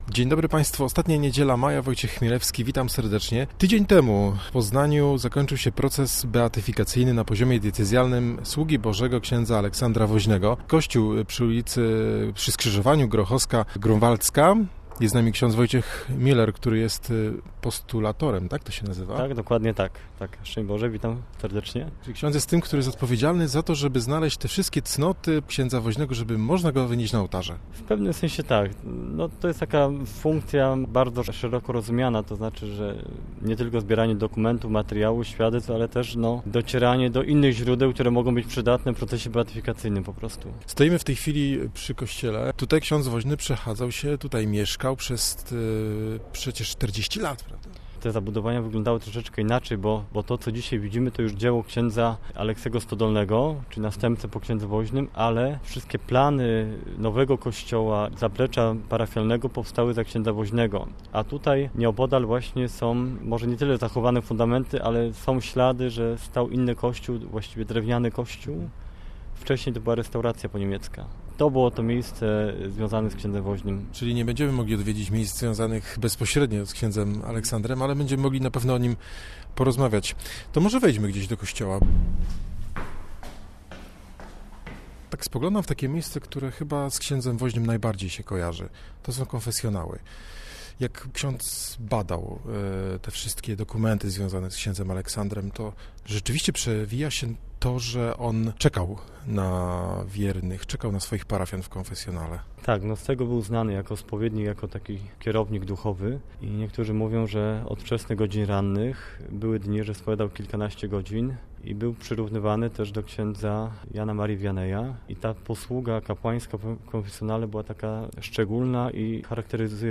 Rozmowa o procesie beatyfikacyjnym ks. Aleksandra Woźnego